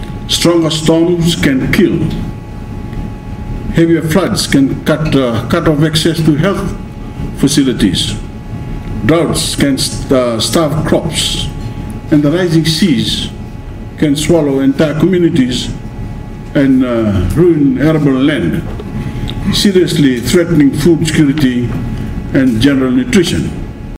Bainimarama made these bold remarks at the Sustainable Surgical Systems and Planetary Health event at the Harvard Club in New York yesterday.
He was addressing health sector stakeholders across the global surgery and planetary health communities.